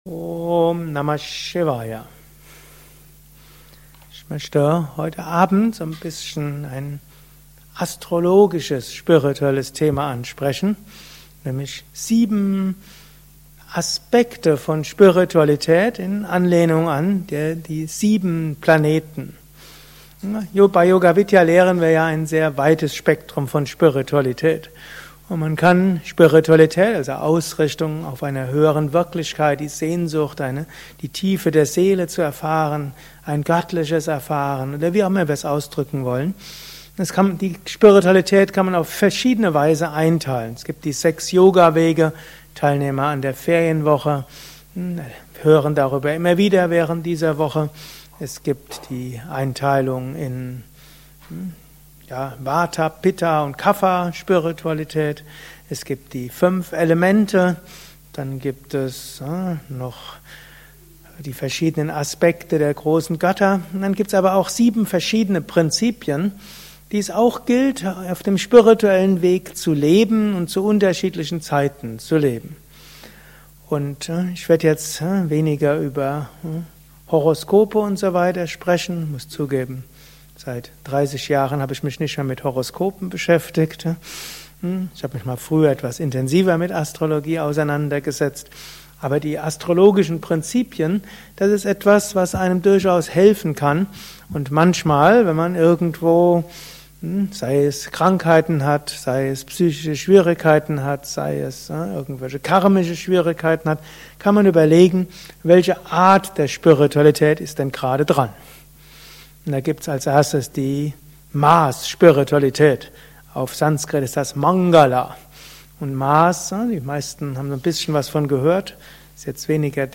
Gelesen im Anschluss nach einer Meditation im Haus Yoga Vidya Bad Meinberg.